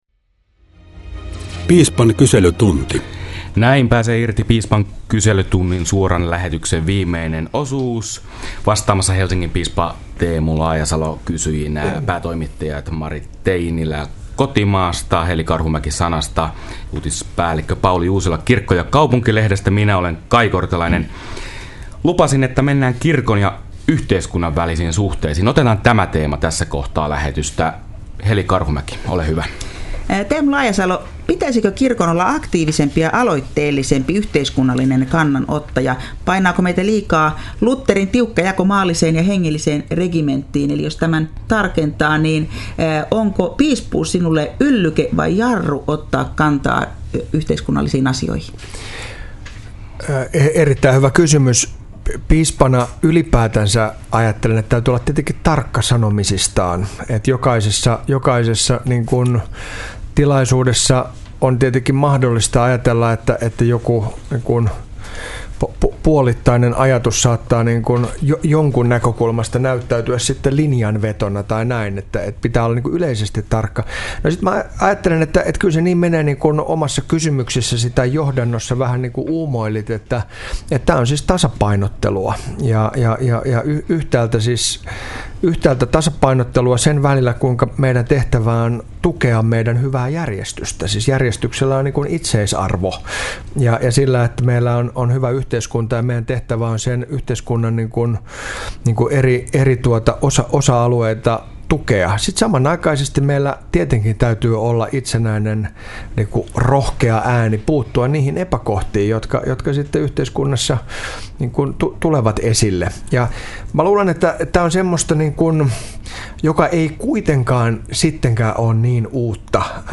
Kerran kuukaudessa kuultavassa suorassa lähetyksessä eri medioita edustavien toimittajien kysymyksiin saapuu vastaamaan vuorollaan kukin Suomen evankelis-luterilaisen kirkon piispoista.
Syyskauden avaa Helsingin piispa Teemu Laajasalon vierailu Radio Dein studiossa keskiviikkoaamuna 5. syyskuuta.